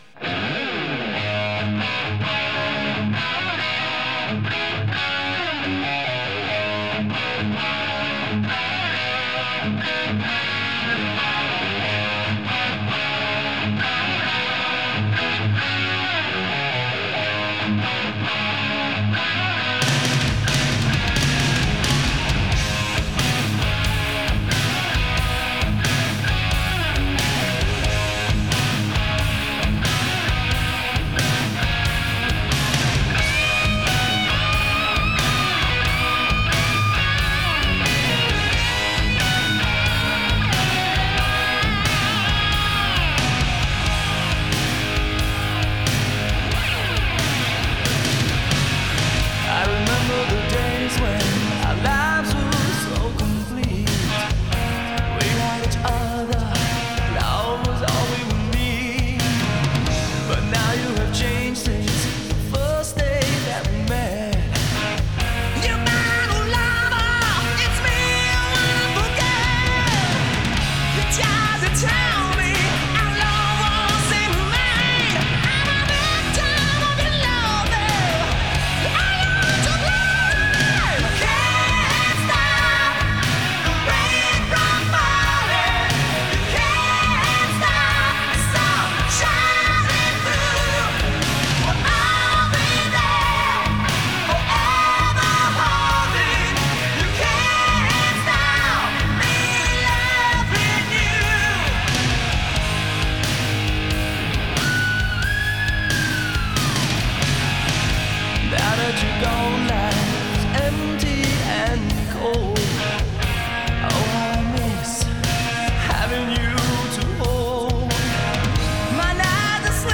американская глэм-метал-группа